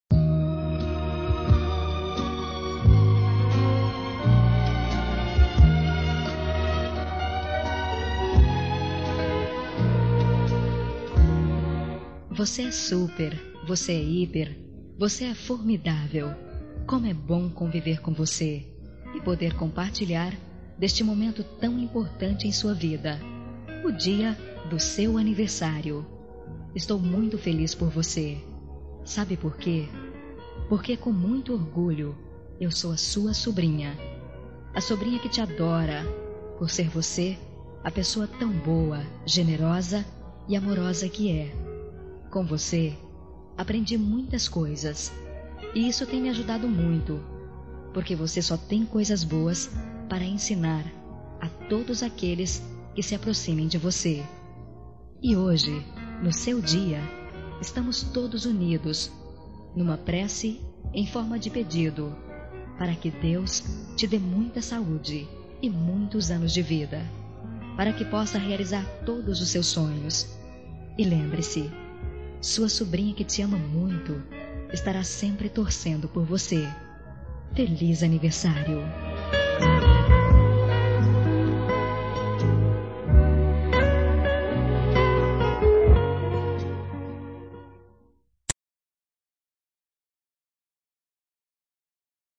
Telemensagem Aniversário de Tia – Voz Feminina – Cód: 2003